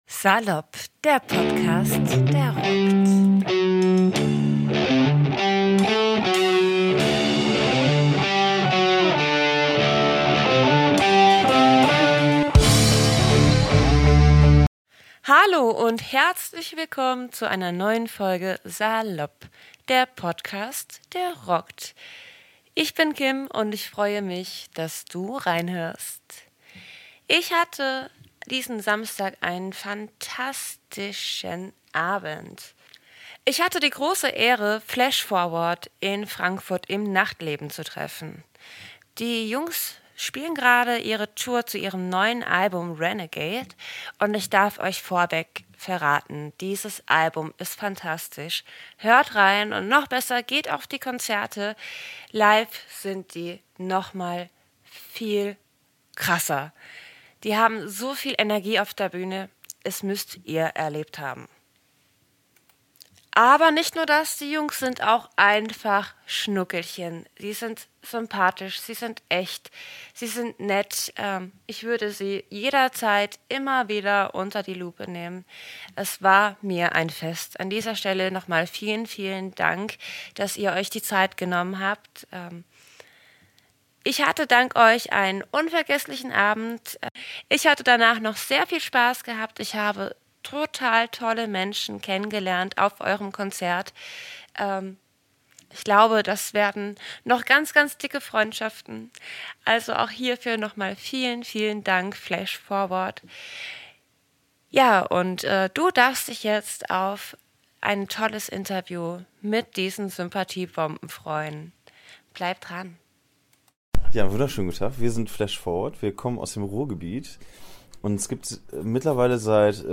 Ich hatte die große Ehre, die fantastischen Jungs von Flash Forward in Frankfurt im Nachtleben zu interviewen.